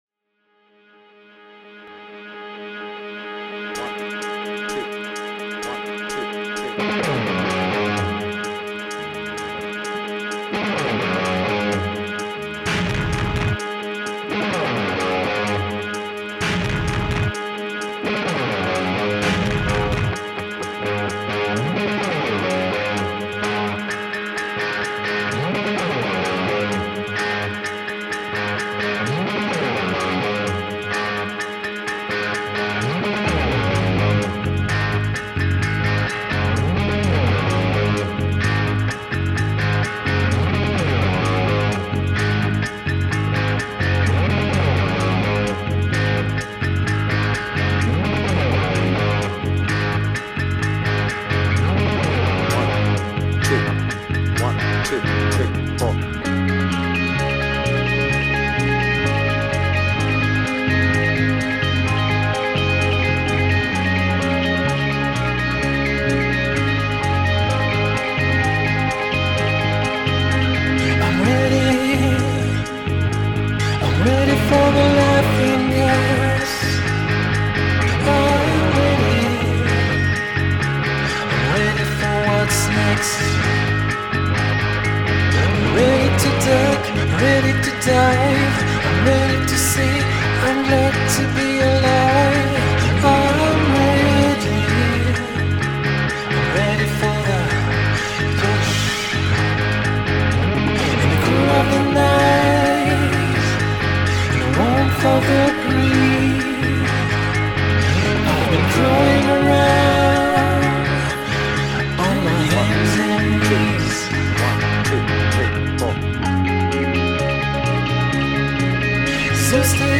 BPM : 128
Tuning : Eb
Without vocals